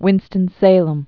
(wĭnstən-sāləm)